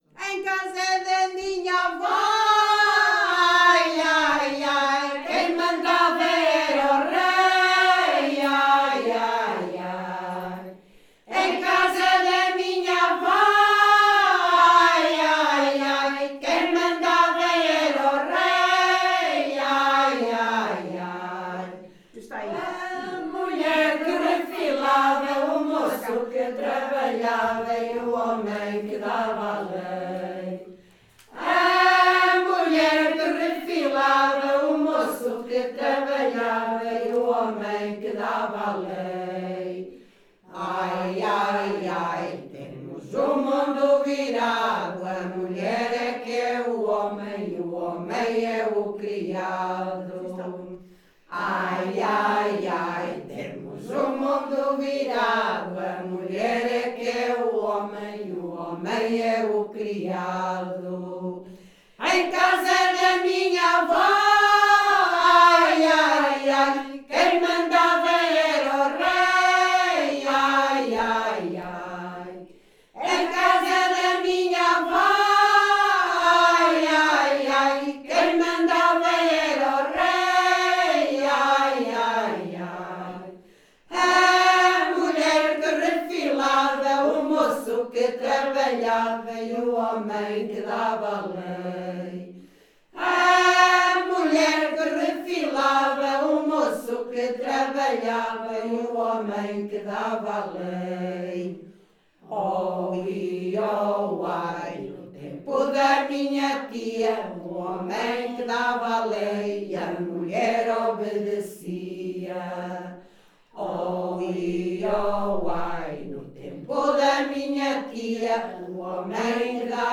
Grupo Etnográfico de Trajes e Cantares do Linho de Várzea de Calde - Ensaio - Em casa da minha avó.